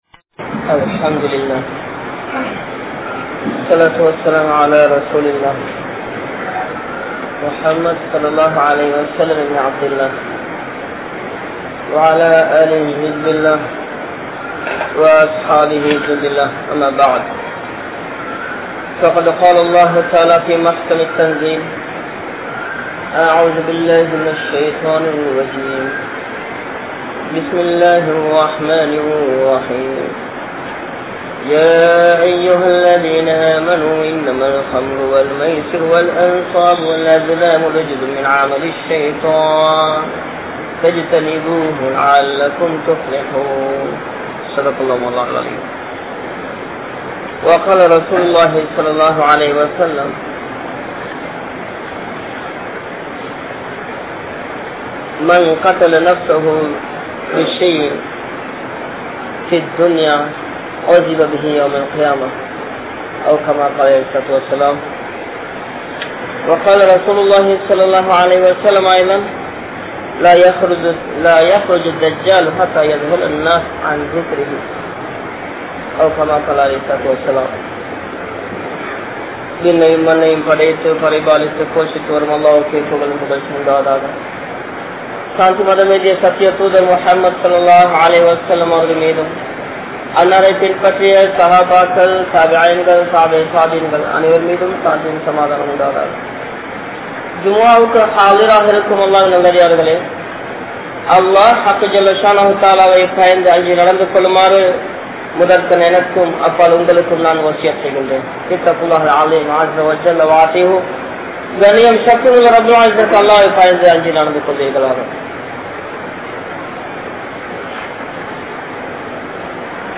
Qiyaamath Naalin Adaiyaalangal (கியாமத் நாளின் அடையாளங்கள்) | Audio Bayans | All Ceylon Muslim Youth Community | Addalaichenai
Dehiwela, Junction Jumua Masjith